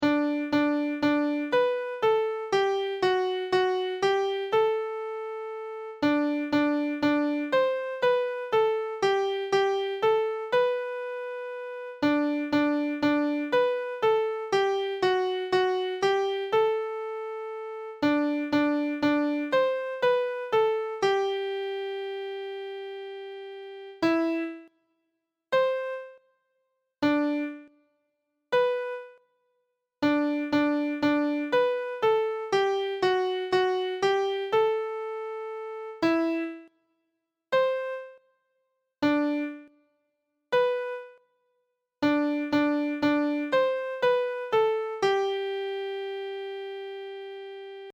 In this action song everyone stands in a circle with an arm around each neighbour's waist and the right leg across in front of her neighbour's left. The whole circle sways to the left for one bar, then to the righ in time to the waltz music.
Begin slowly and sing the song three times getting gradually  faster and switing further.